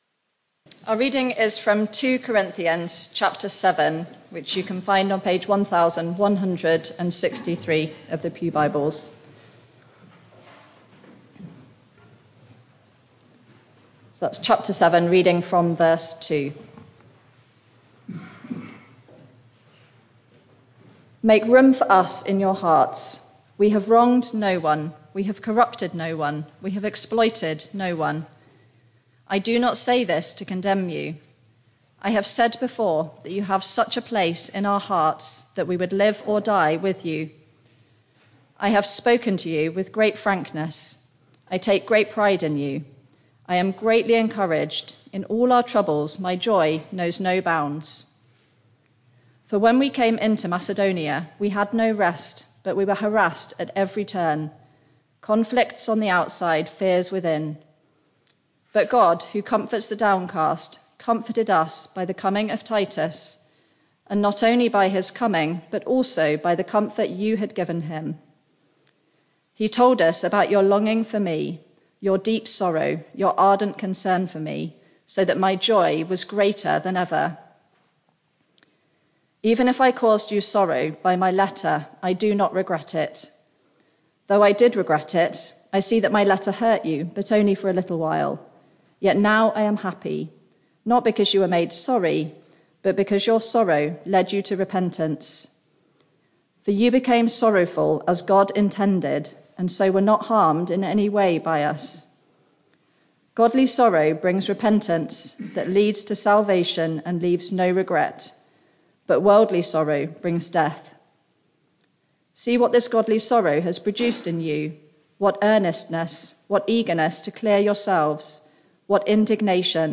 Barkham Morning Service
Full service Sermon